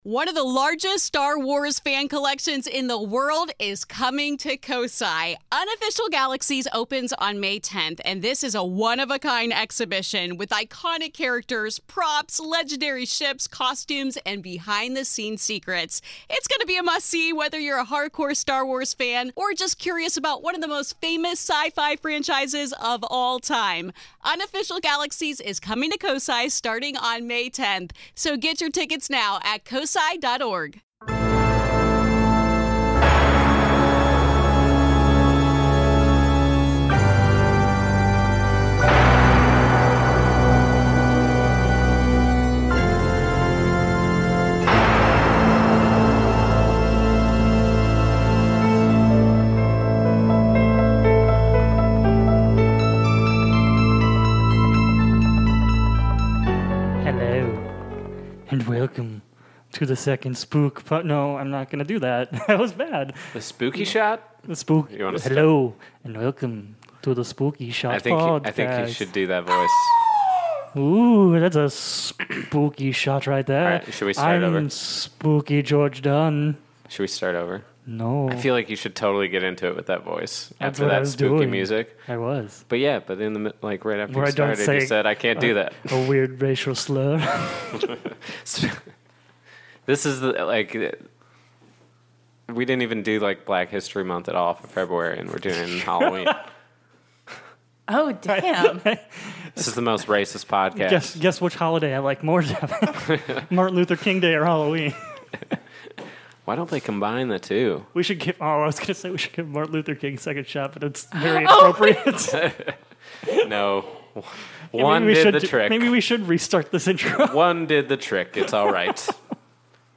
We kick it off with a not so spooky conversation about Ghostbusters 2 for you babies out there. Bill Murray, Harold Ramis, Rick Moranis.